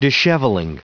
Prononciation du mot dishevelling en anglais (fichier audio)
Prononciation du mot : dishevelling